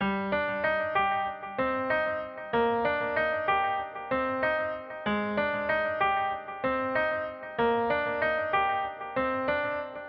Tag: 95 bpm Weird Loops Piano Loops 1.70 MB wav Key : Unknown